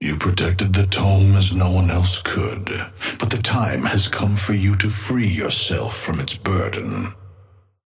Amber Hand voice line - You protected the Tome as no one else could.
Patron_male_ally_atlas_start_02.mp3